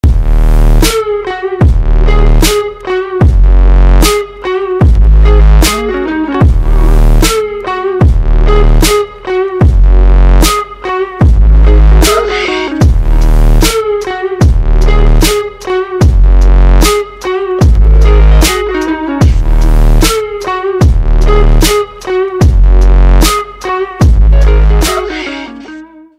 Electronic
мощные басы
без слов
чувственные
электрогитара
alternative
Blues
вздохи